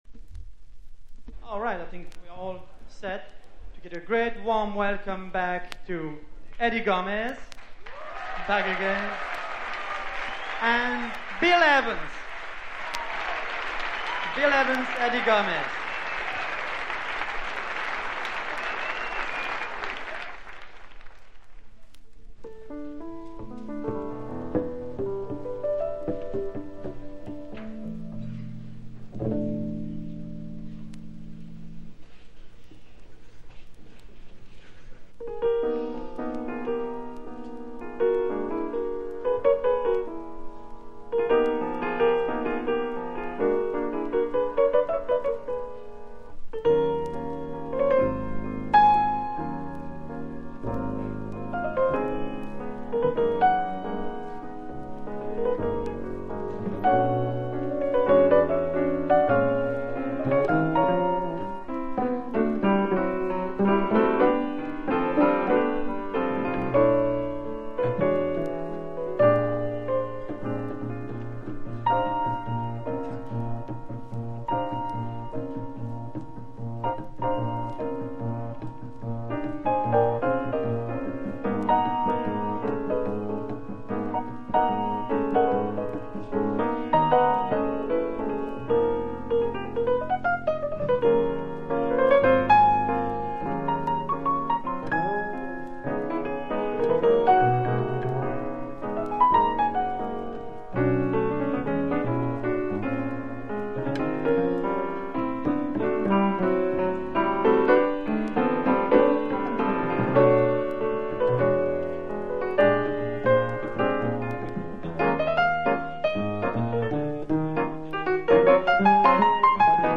（プレスによりチリ、プチ音ある曲あり）※曲名をクリックすると試…